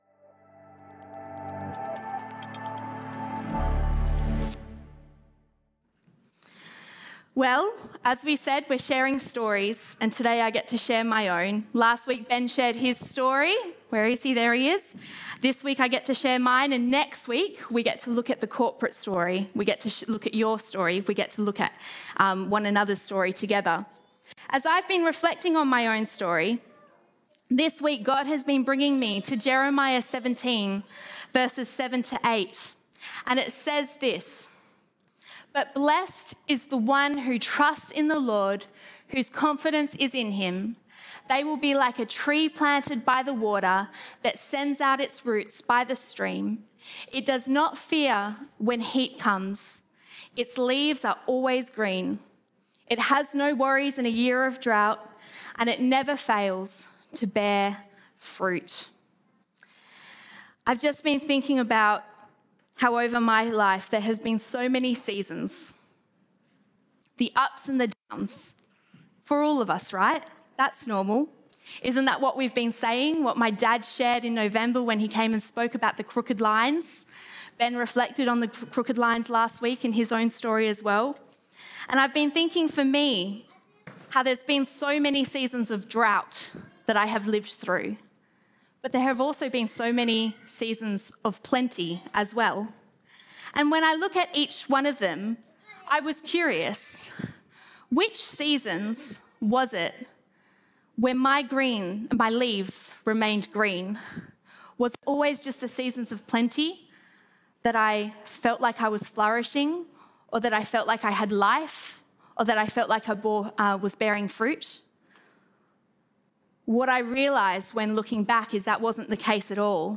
Sermons - Como Baptist Church